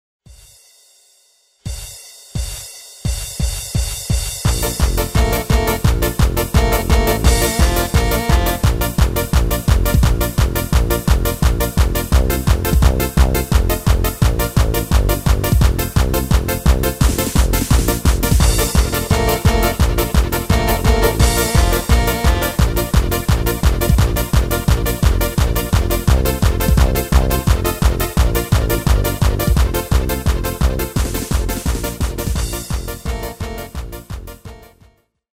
Demo/Koop midifile
Genre: Carnaval / Party / Apres Ski
- Géén vocal harmony tracks
Demo's zijn eigen opnames van onze digitale arrangementen.